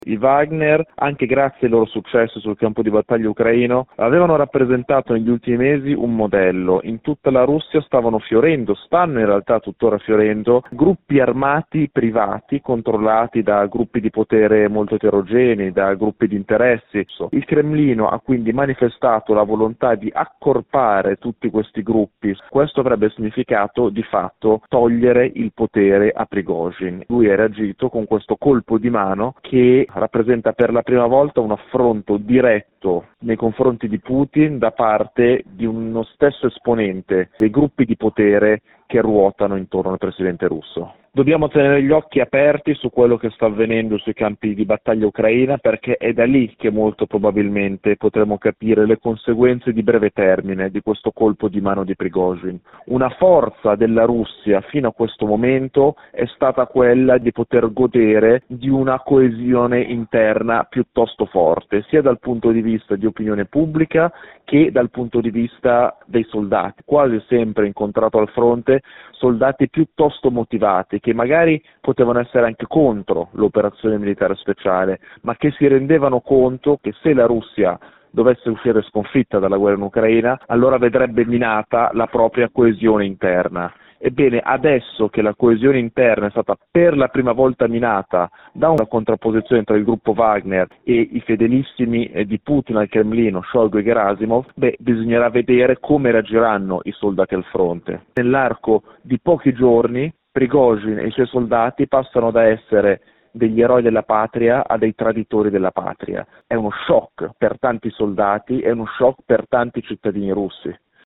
Il racconto